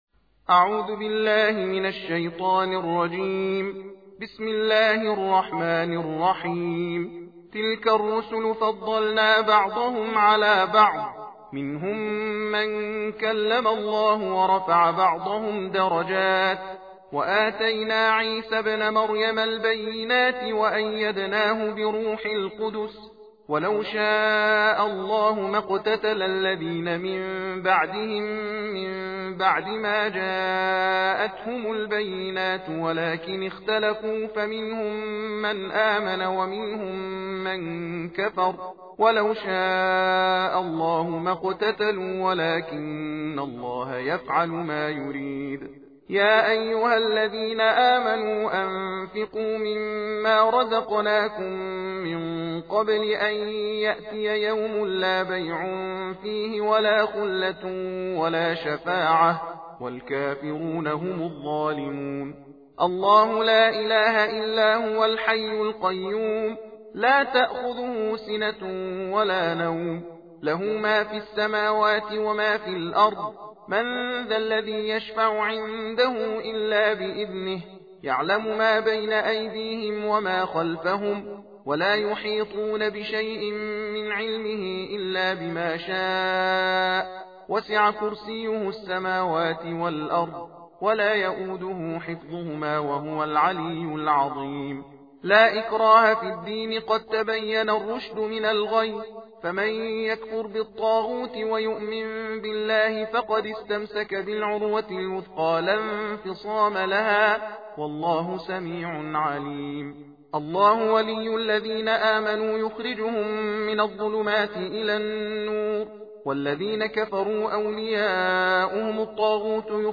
تلاوت جزء سوم قرآن کریم